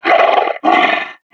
This is an audio clip from the game Team Fortress 2 .
Mercenary_Park_Yeti_statue_growl6.wav